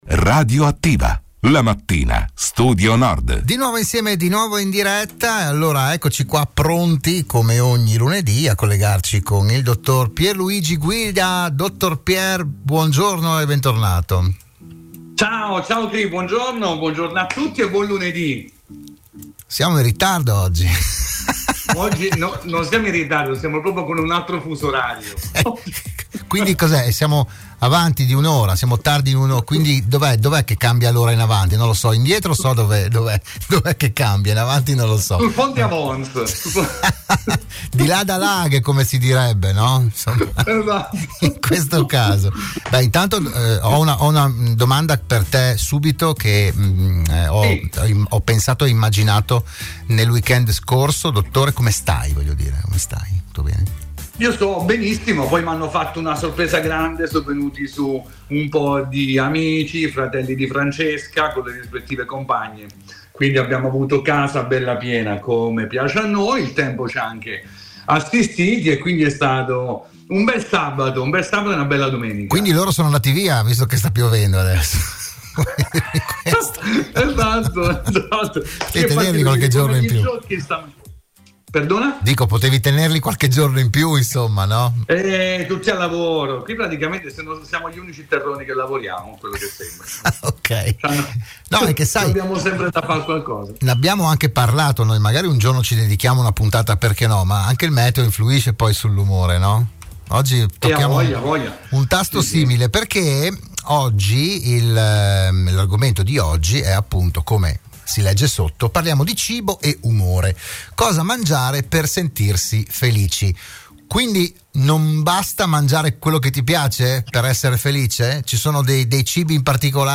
Nuova puntata per “Buongiorno Dottore”, il programma di prevenzione e medicina in onda all’interno della trasmissione di Radio Studio Nord “RadioAttiva”.